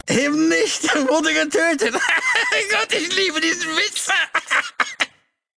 Fallout: Audiodialoge